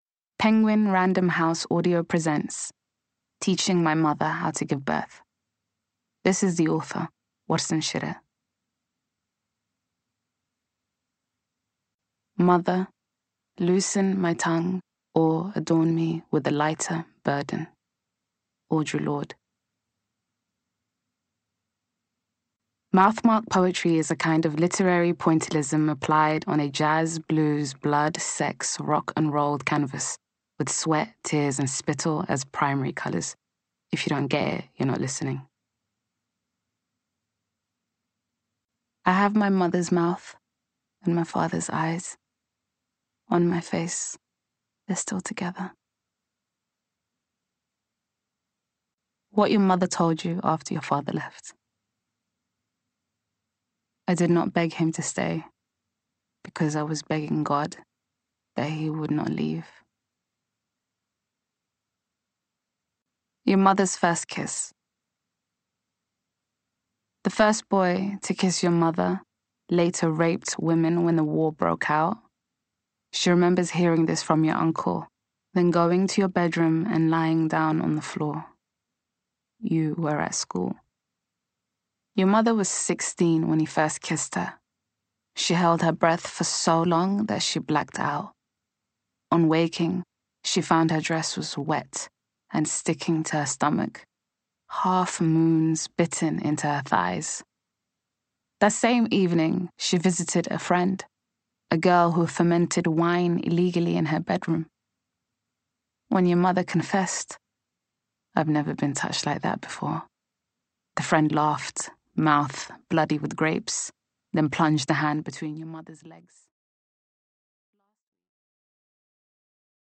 Poetry
Audiobook